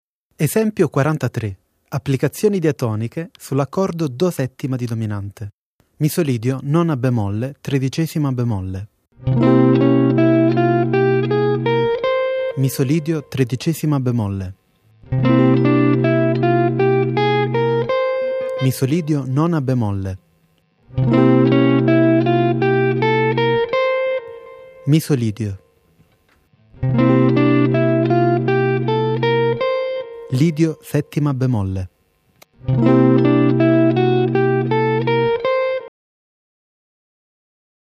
Soluzioni modali su C7
Nome del modo: Misolidio b9 b13